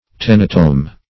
Meaning of tenotome. tenotome synonyms, pronunciation, spelling and more from Free Dictionary.
Search Result for " tenotome" : The Collaborative International Dictionary of English v.0.48: Tenotome \Ten"o*tome\, n. (Surg.) A slender knife for use in the operation of tenotomy.